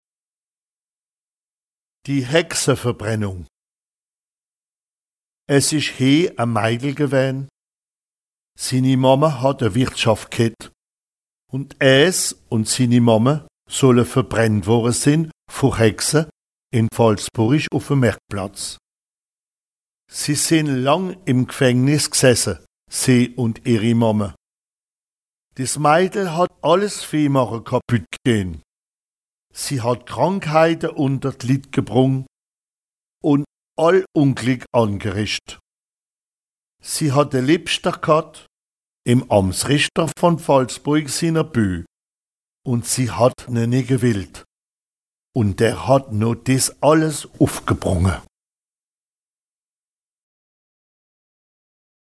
Contes et récits enregistrés dans les communes de Dabo, Hultehouse, Phalsbourg, Henridorff et de Berling.